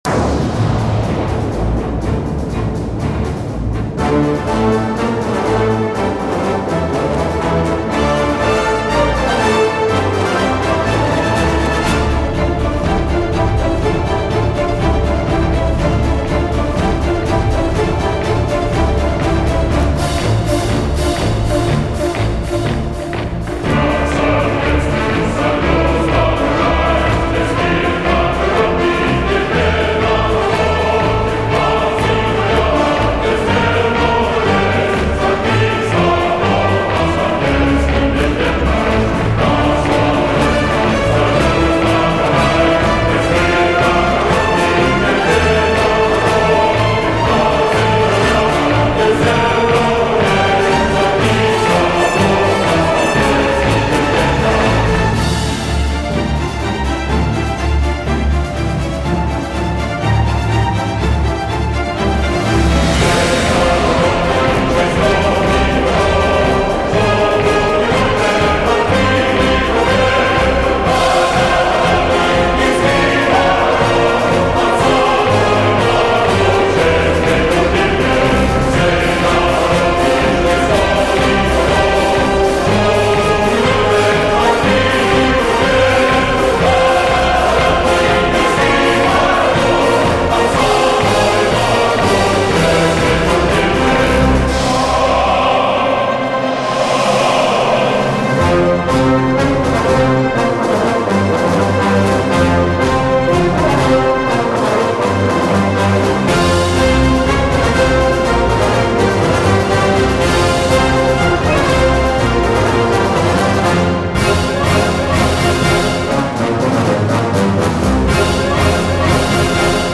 本曲气势恢宏，听了使人热血沸腾。